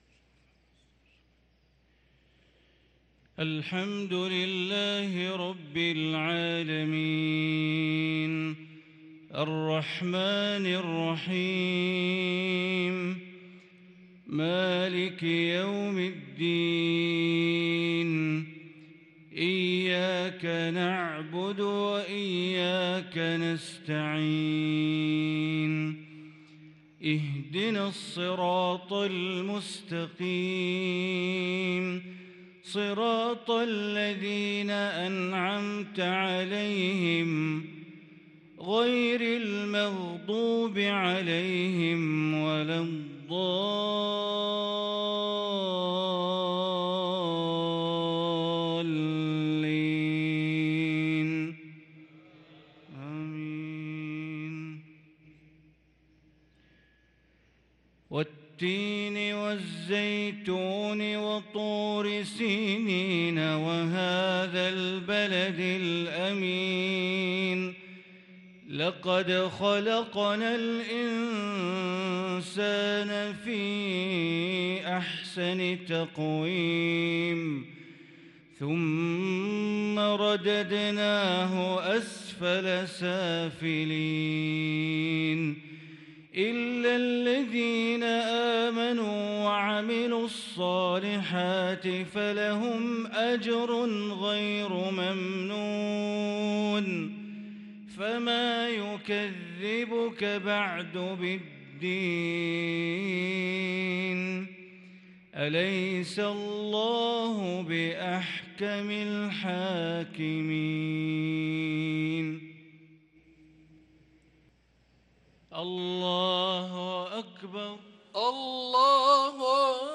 صلاة المغرب للقارئ بندر بليلة 22 ربيع الآخر 1444 هـ
تِلَاوَات الْحَرَمَيْن .